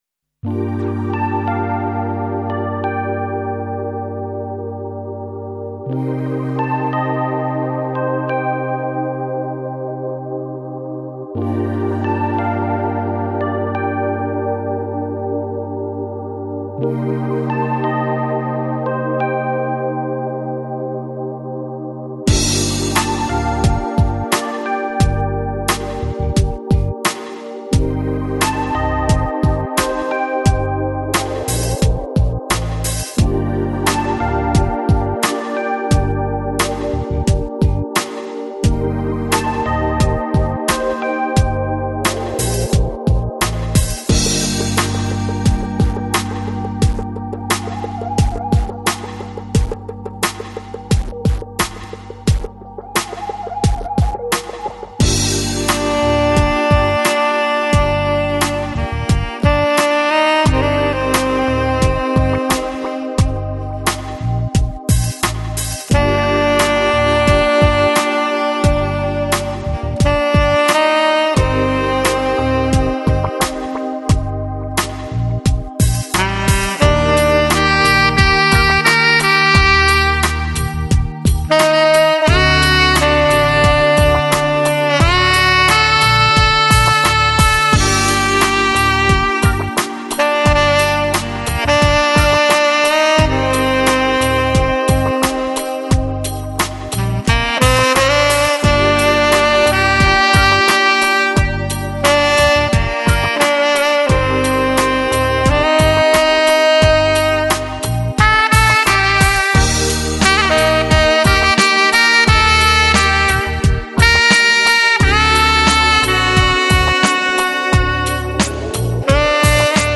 Electronic, Lounge, Chill Out, Jazz